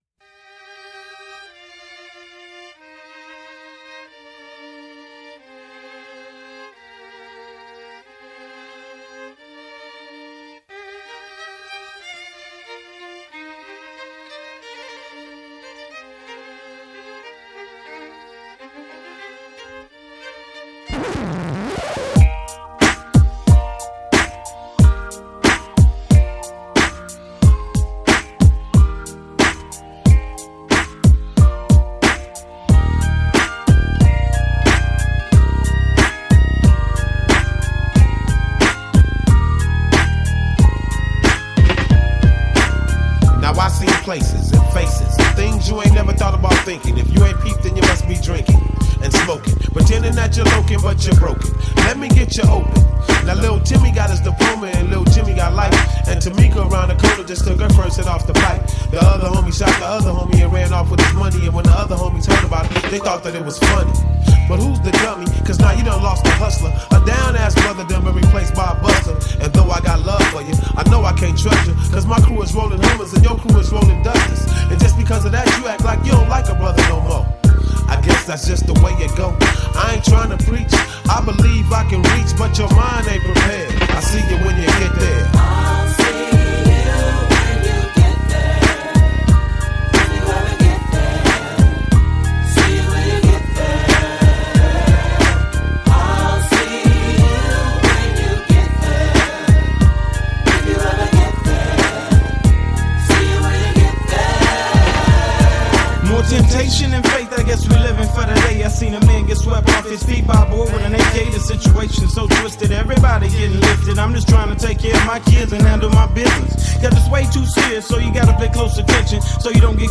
Жанр: Rap, Hip Hop